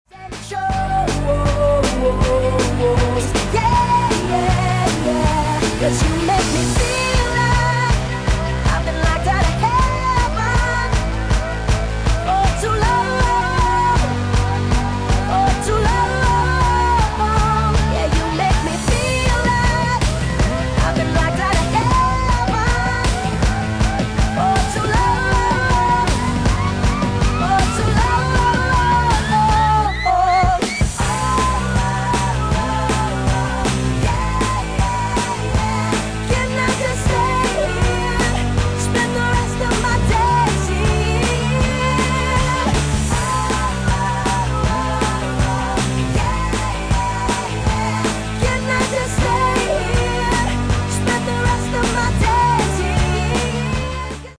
sped up!